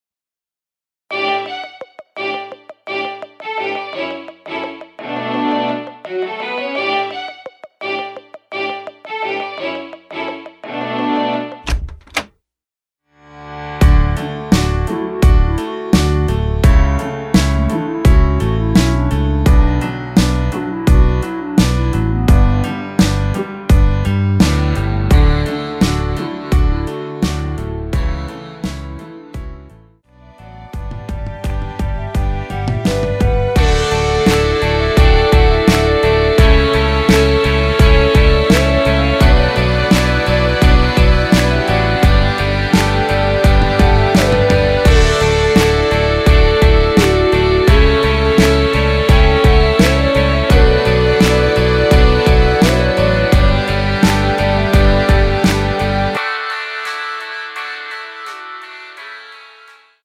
멜로디 MR입니다.
원키에서(-1)내린 멜로디 포함된 MR입니다.
D
앞부분30초, 뒷부분30초씩 편집해서 올려 드리고 있습니다.
중간에 음이 끈어지고 다시 나오는 이유는